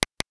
Geluidimpuls in een semi-anechoïsch veld voor d=30 m
De geluiddemonstraties hiernaast laten een geluidimpuls horen in een semi-anechoïsch veld, waarbij de afstand tussen ontvanger en reflecterende wand respectievelijk 10 m (vertraging 58 ms) en 30 m (174 ms) bedraagt.
Impulse_echo30m.wav